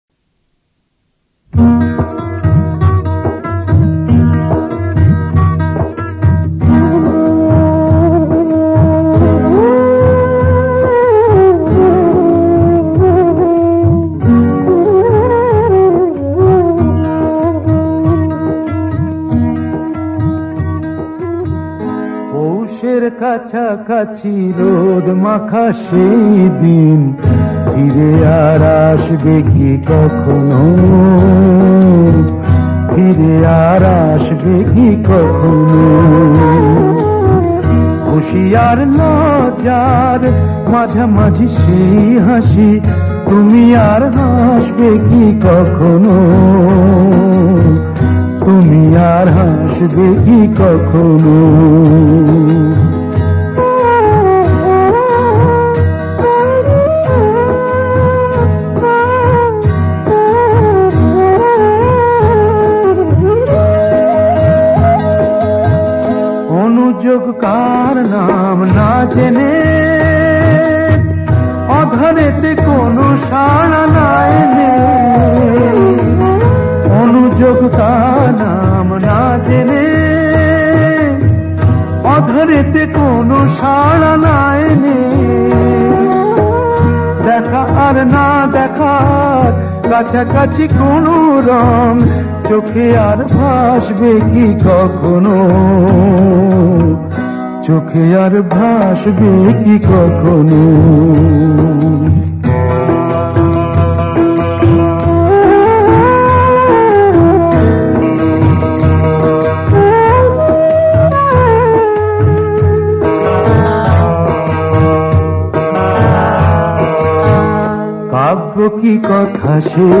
Genre Bangla Old Song